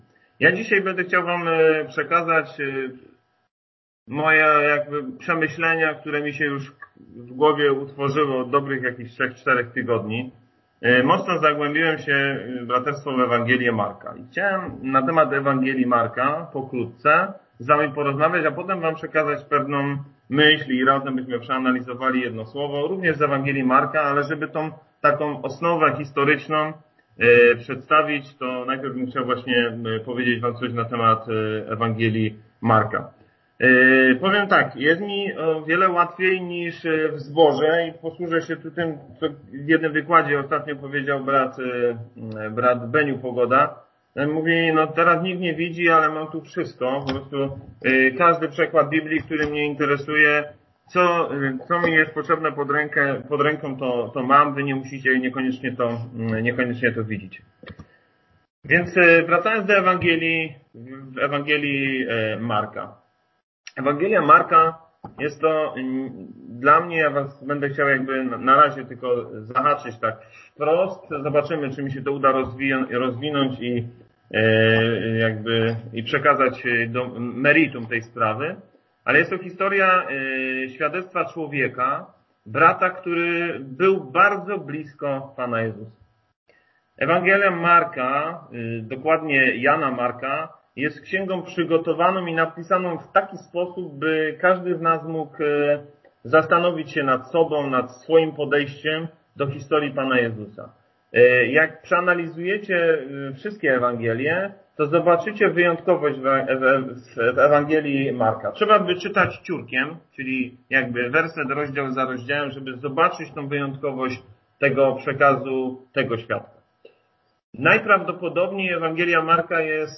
Wykłady zbór Poznań